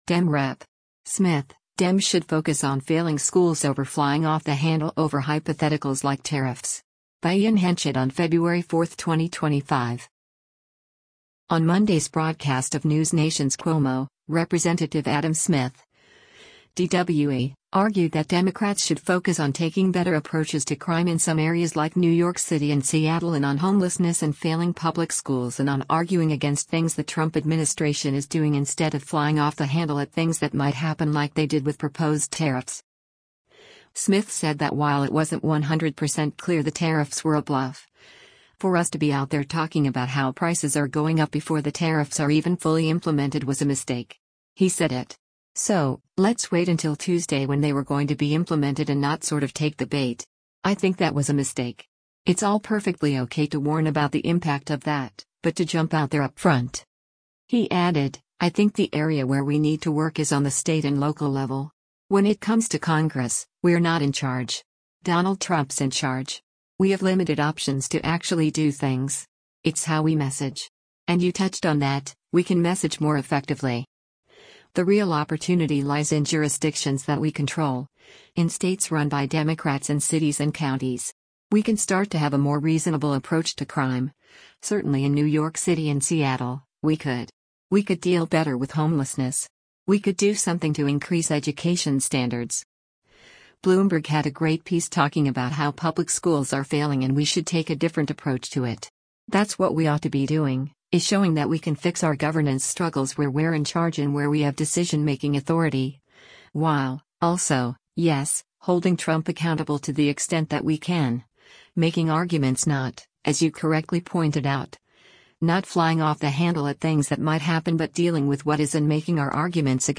On Monday’s broadcast of NewsNation’s “Cuomo,” Rep. Adam Smith (D-WA) argued that Democrats should focus on taking better approaches to crime in some areas like New York City and Seattle and on homelessness and “failing” public schools and on arguing against things the Trump administration is doing instead of “flying off the handle at things that might happen” like they did with proposed tariffs.